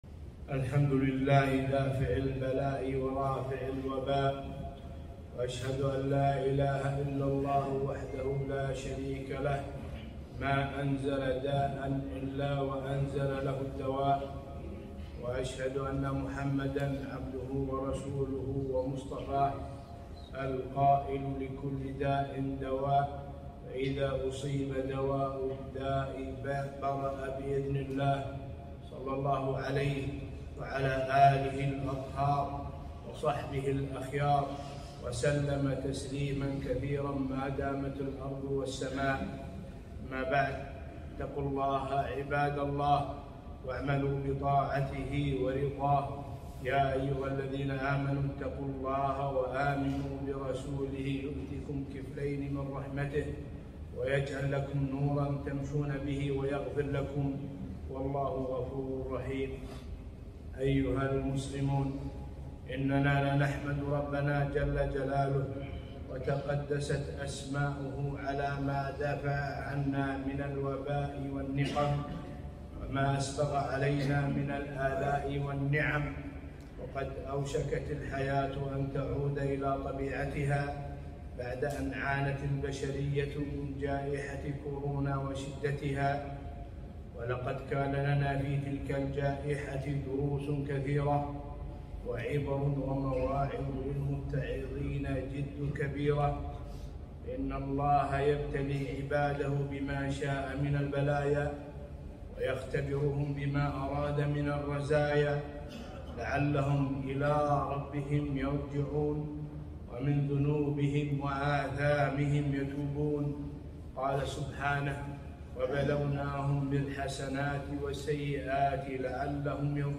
خطبة - الدروس المستفادة من جائحة كورونا